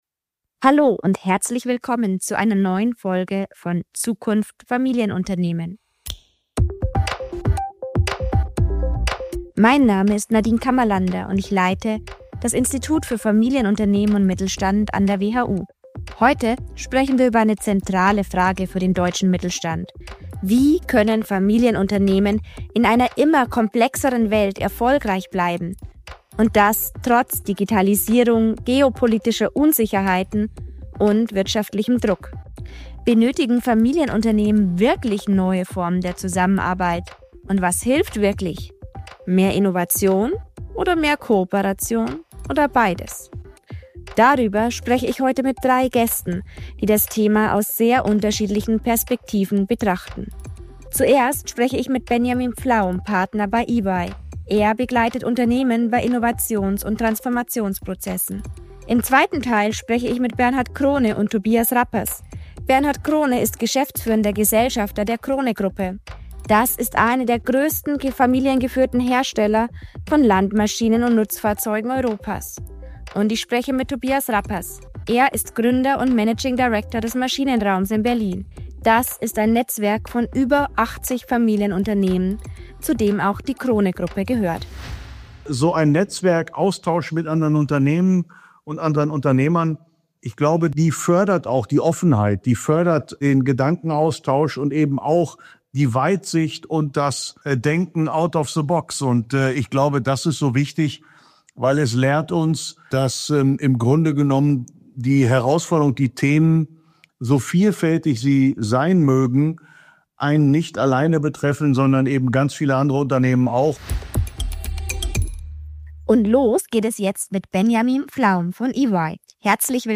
Im gemeinsamen Gespräch beschreibt er, warum Austausch und Kooperation im Netzwerk so gut gelingen.